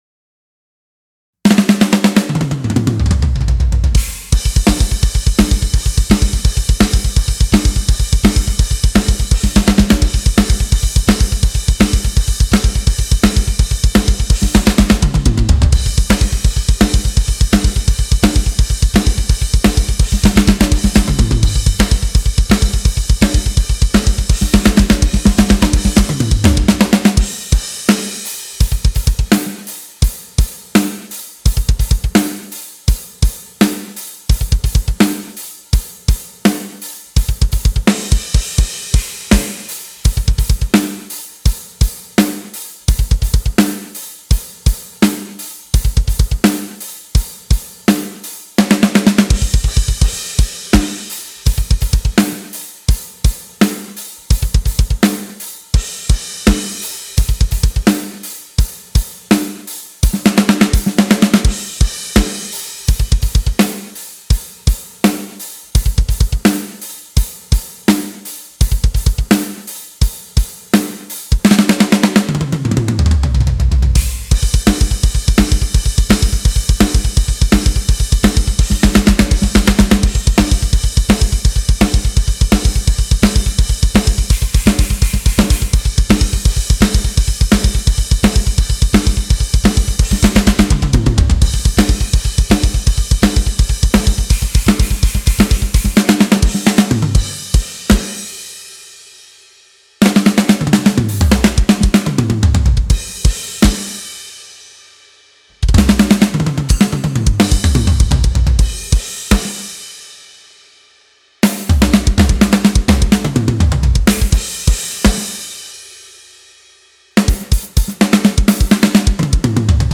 Classic Metal
Genre:Classic Metal, Rock
Tempo:168 BPM (4/4)
Kit:Sonor SQ2 beech 22"
Mics:18 channels